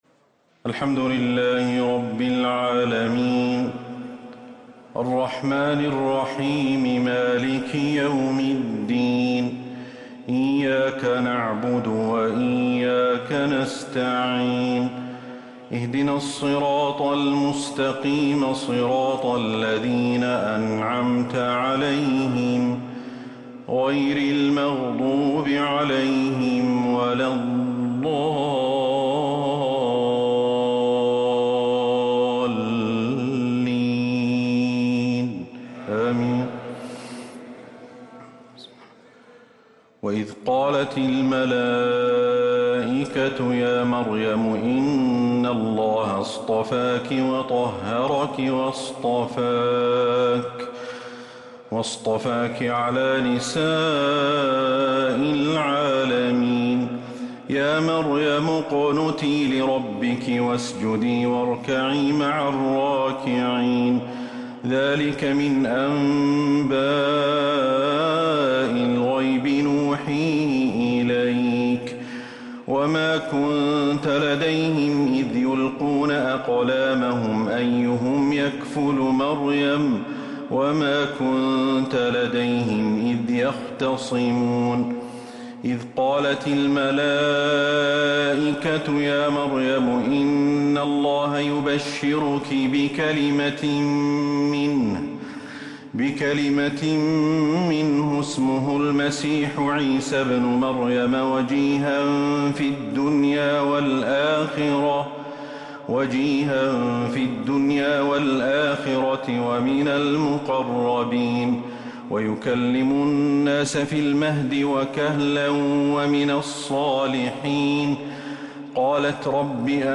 تراويح ليلة 4 رمضان 1444هـ من سورة آل عمران {42-92} Taraweeh 4st night Ramadan 1444H Surah Aal-i-Imraan > تراويح الحرم النبوي عام 1444 🕌 > التراويح - تلاوات الحرمين